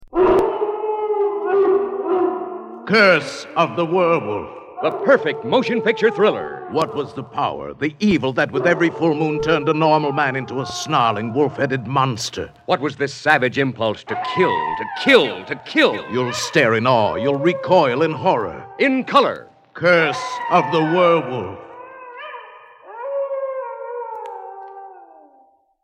Howl With Some Werewolf Movie Radio Spots